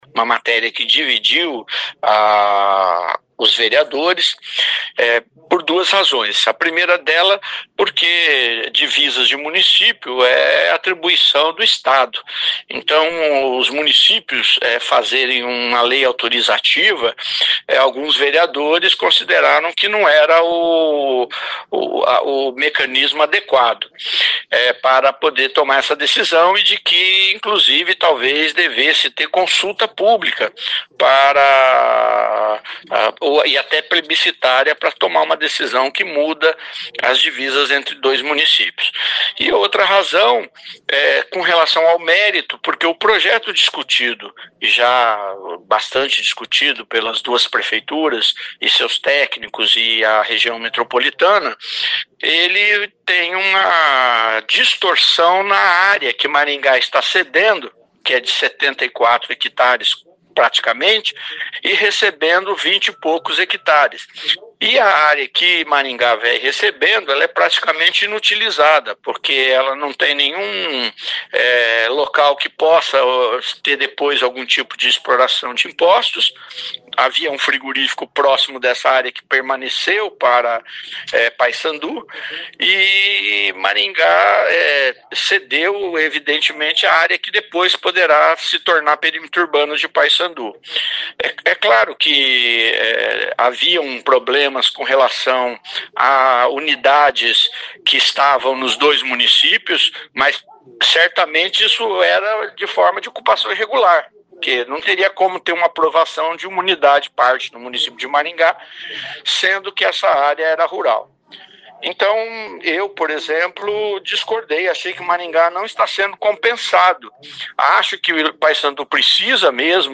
O vereador Sidnei Telles explica por que a polêmica.